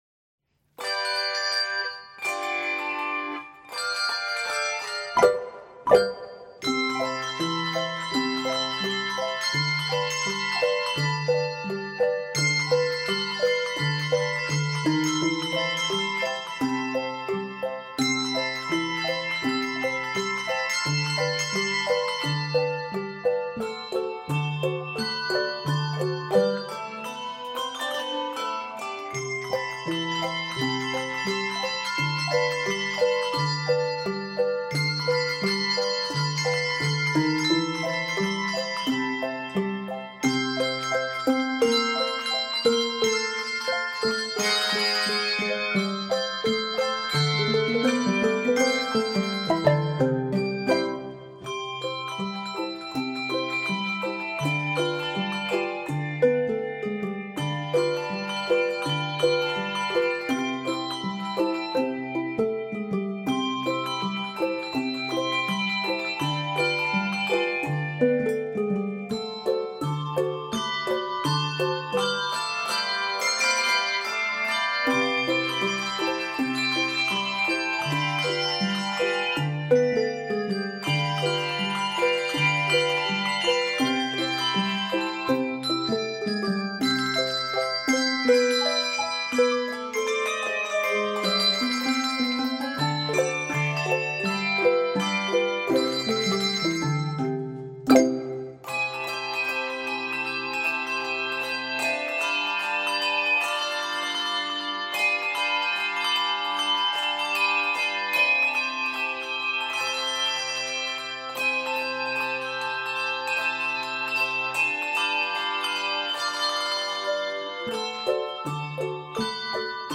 The ragtime and jazz standard is transcribed for handbells
Keys of C Major and Eb Major.